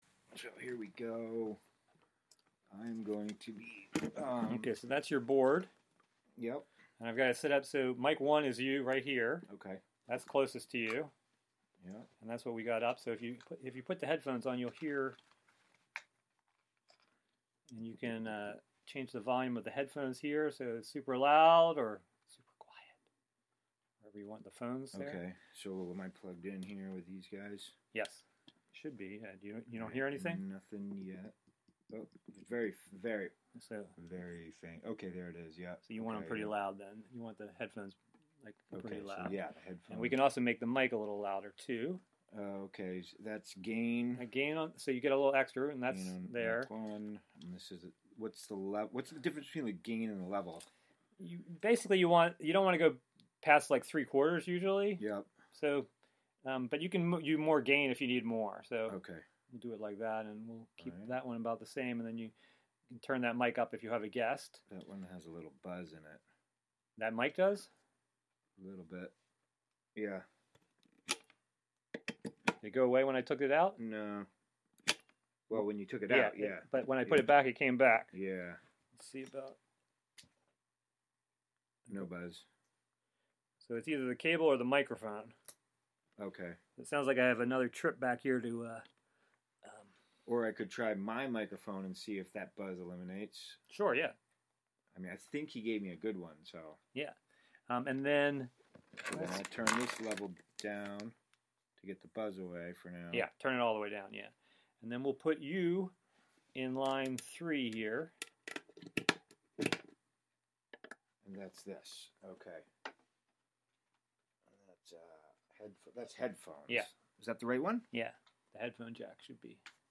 Prime Jive: Monday Afternoon Show- Live from Housatonic, MA (Audio)